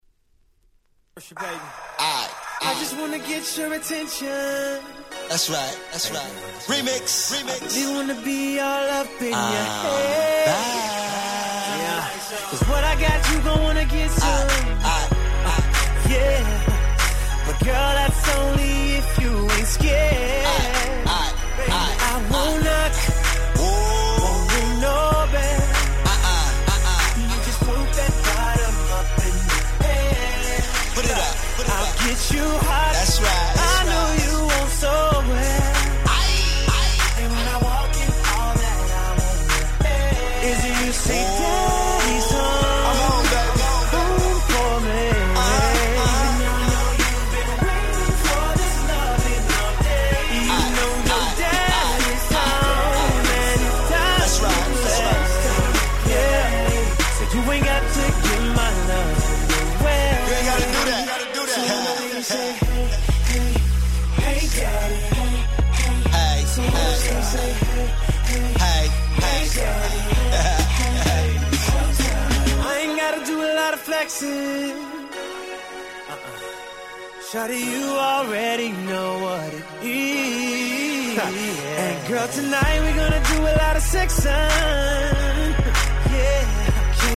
10' Smash Hit R&B !!
彼らしいMellowなMidナンバー！